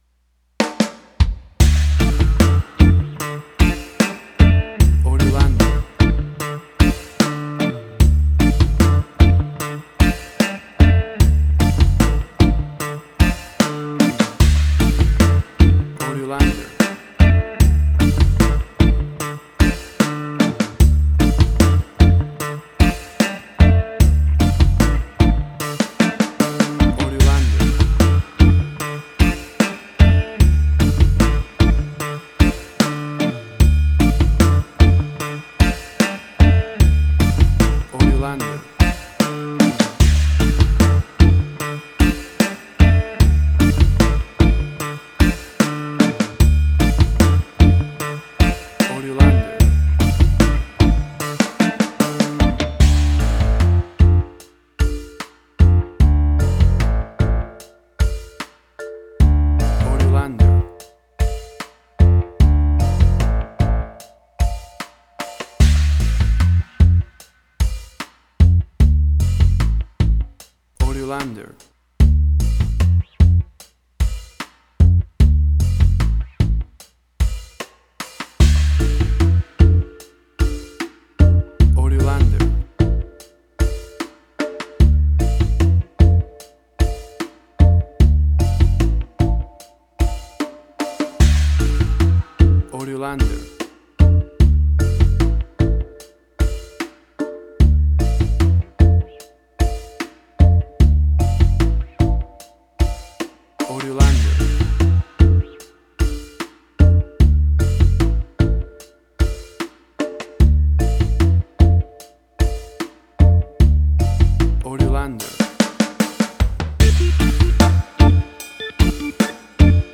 Reggae caribbean Dub Roots
Tempo (BPM): 75